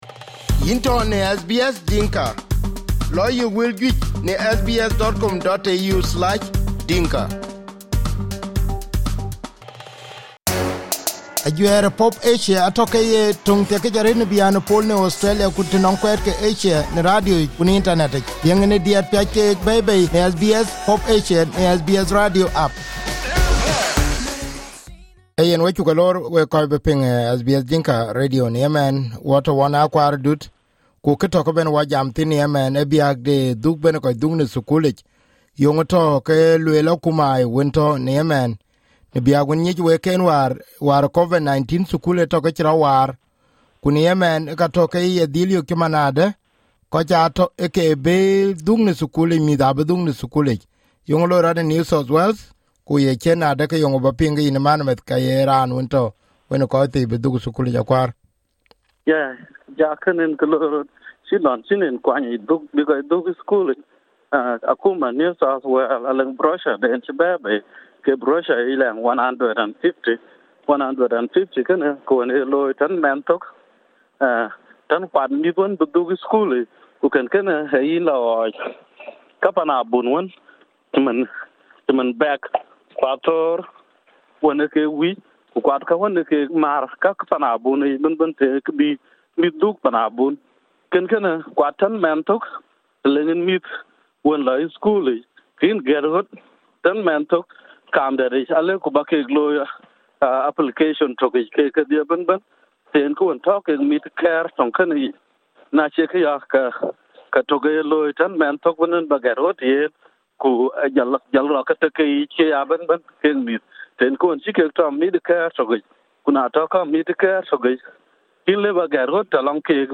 in SBS Radio studio in Sydney